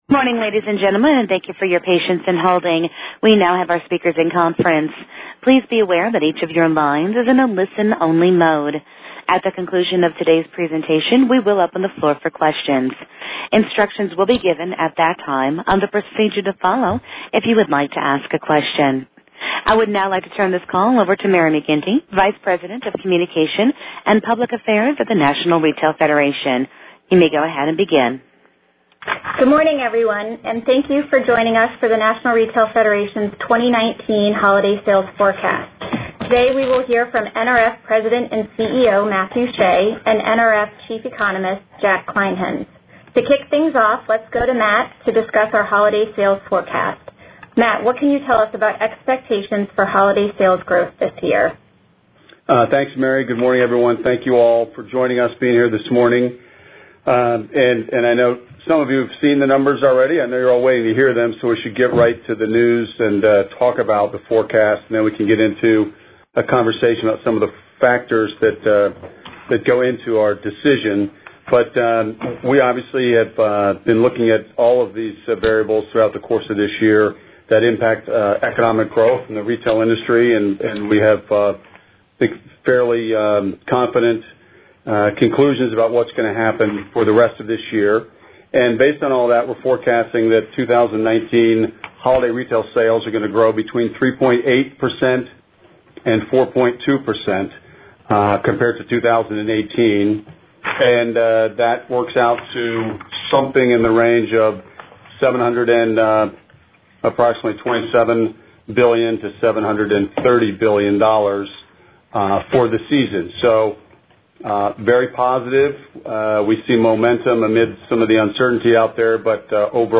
media call recording.mp3